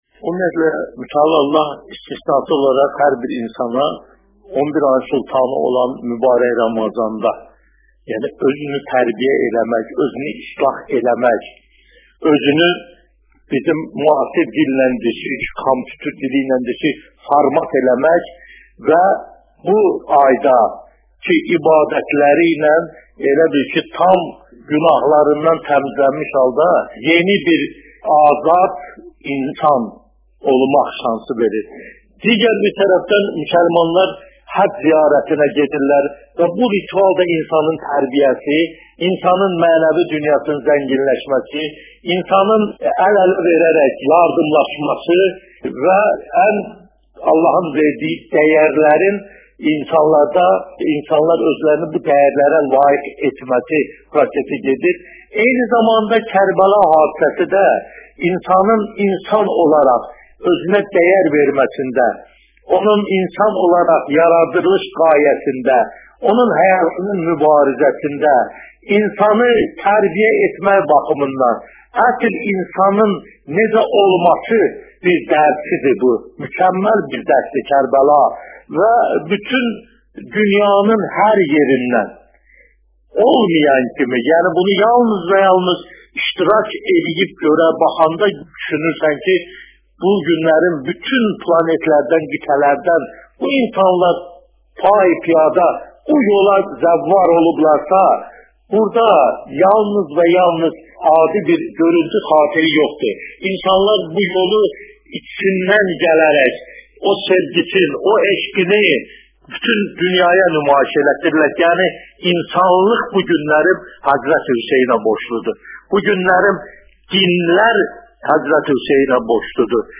Səhər Beynəxl Televiziyasının azəri radiosuna eksklüziv müsahibə verərək Kərbəla hadisəsi və Ərbəyin haqda fikirərini bölüşüb .